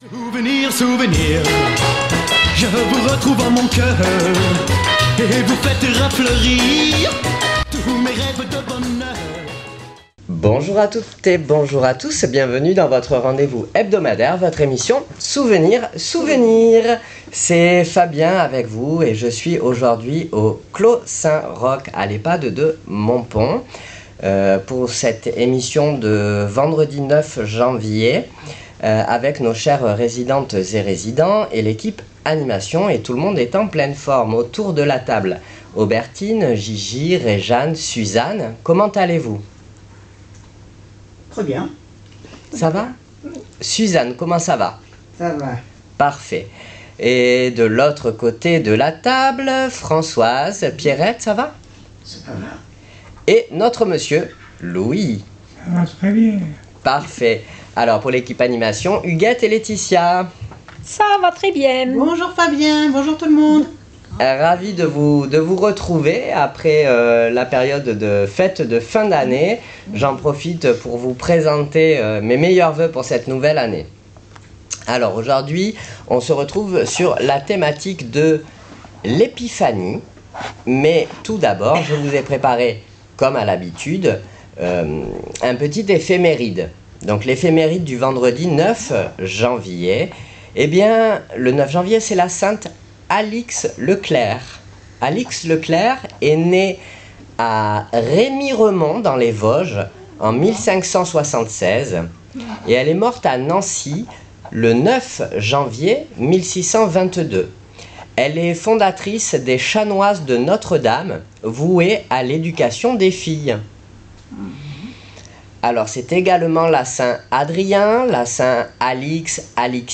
Souvenirs Souvenirs 09.01.26 à l'Ehpad de Montpon " L'épiphanie "